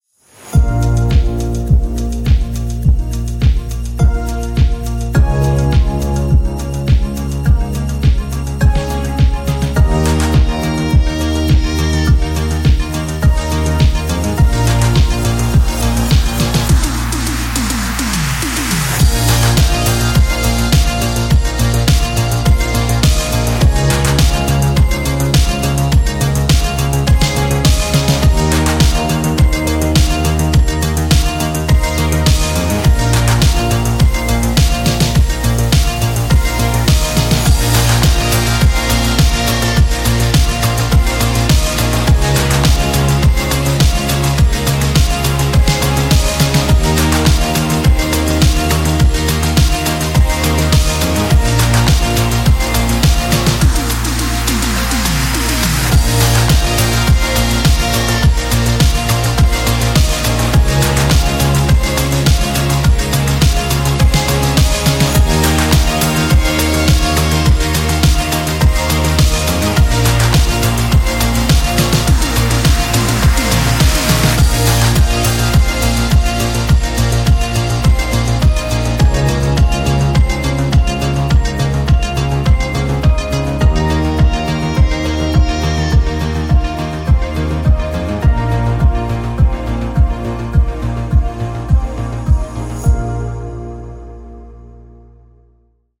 9 - Retro Atmosphere Soundscape